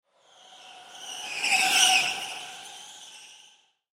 Скрип колес картинга в вираже